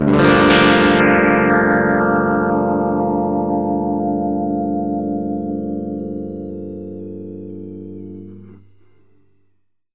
5. Implement an \(m\)-fold Wah-Wah effect with increasing \(m\). Set \(f_c\) to 3500Hz and use \(q=0.5\). Start with \(m=1\), and increase \(m\) by 1 every 0.5 seconds, The result should sound like
wahwahinc.mp3